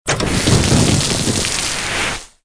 AA_throw_stormcloud_miss.ogg